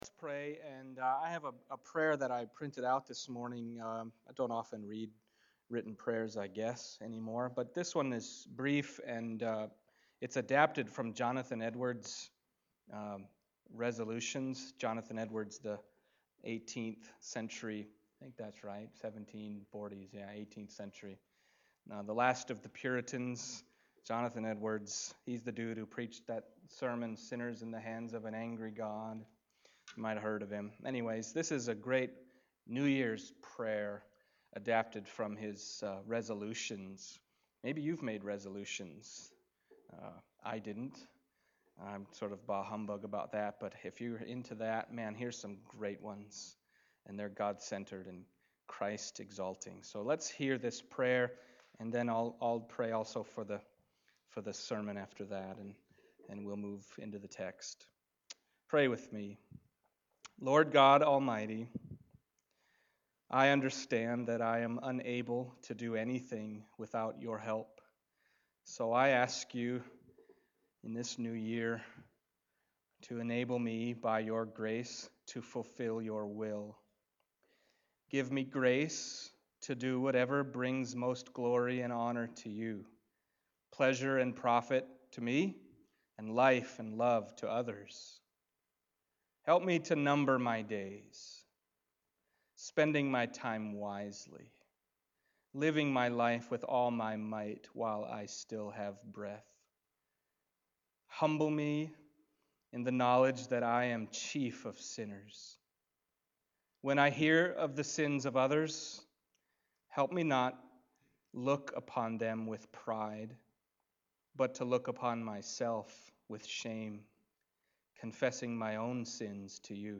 John 6:1-15 Service Type: Sunday Morning John 6:1-15 « How Did People Know Jesus Was Coming?